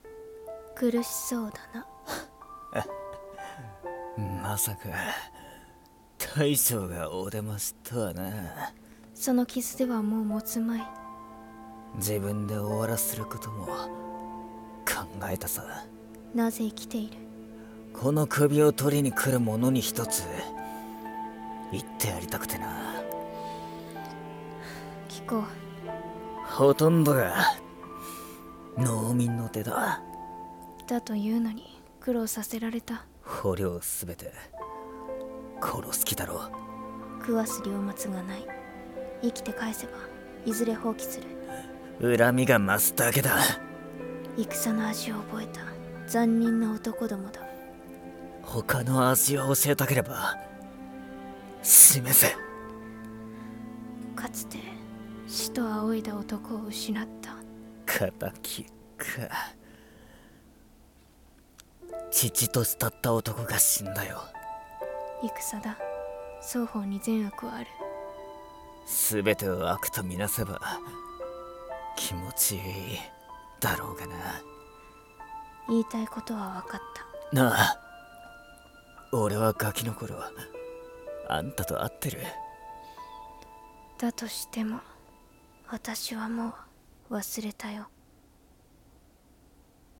【声劇台本】篝火